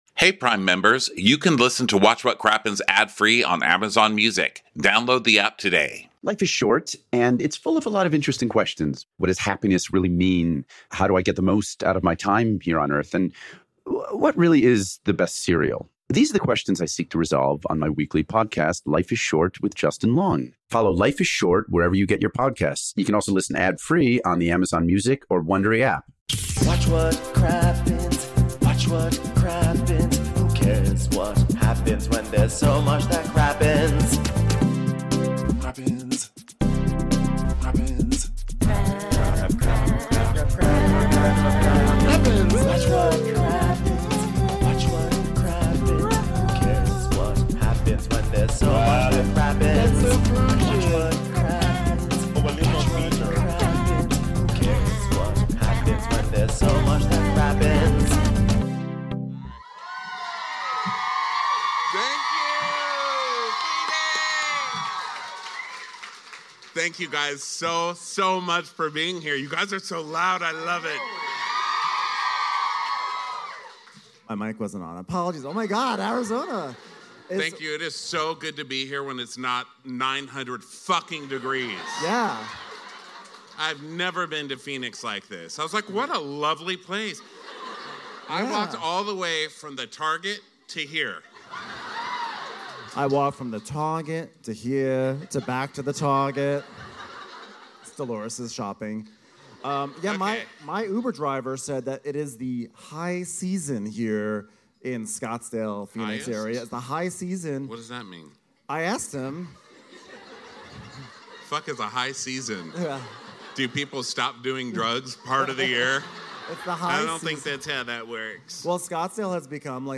RHOM: My Left Out Foot - Live from Scottsdale